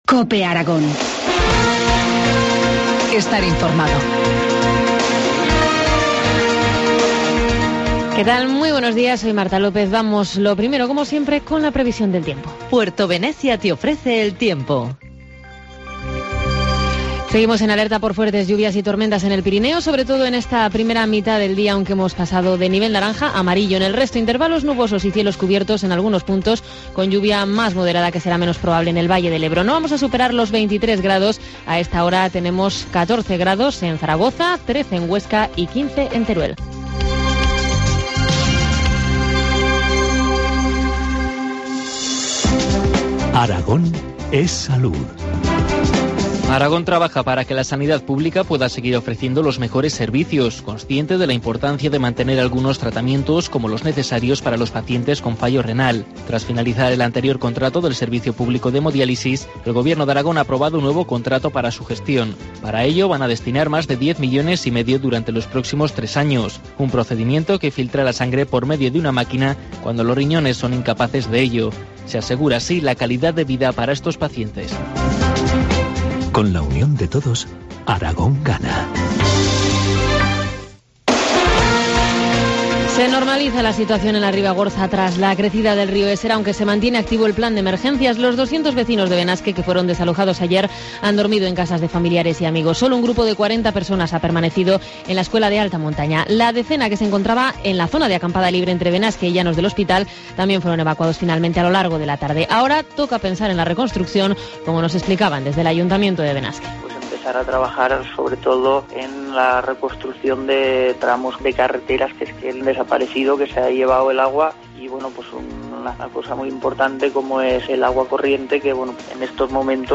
Informativo matinal, miércoles 19 de junio, 8.25 horas